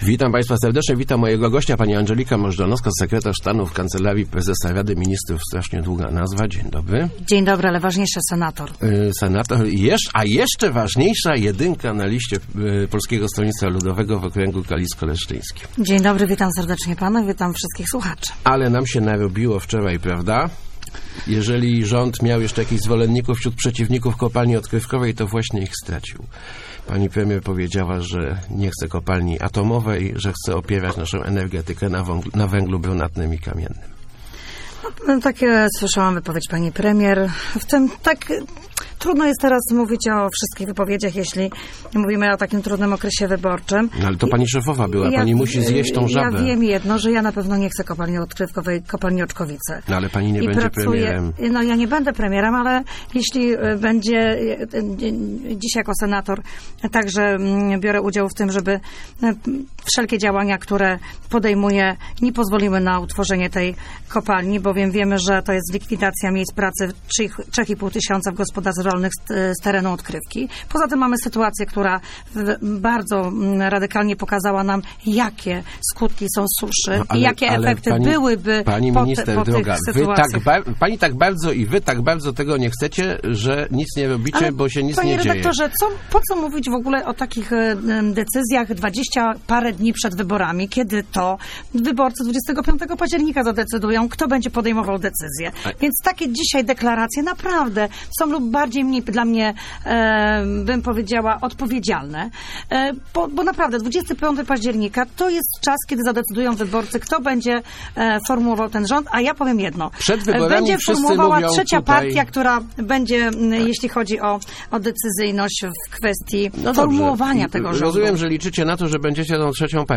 "Jedynka" PSL w Rozmowach Elki. Możdżanowska: to wyborcy zdecydują o kopalni